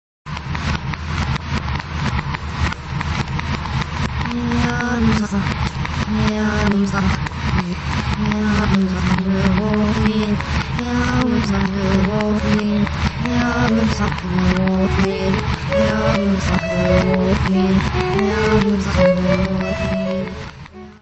Teclados e Guitarra
Violino e Guitarra
Saxofone
Voz e trompete
Percussão
: stereo; 12 cm
Music Category/Genre:  New Musical Tendencies